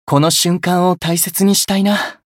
觉醒语音 この瞬間を大切にしたいな 媒体文件:missionchara_voice_718.mp3